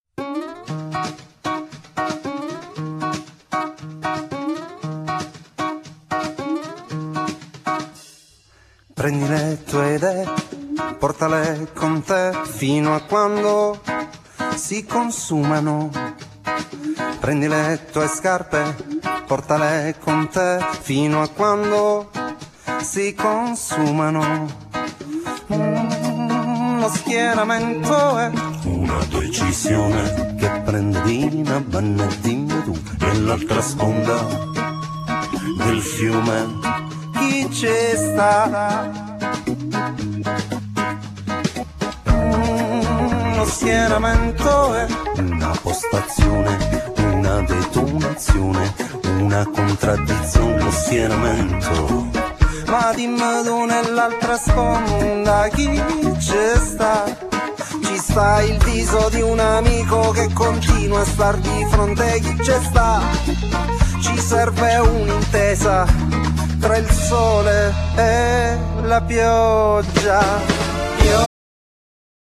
Genere : Etno Folk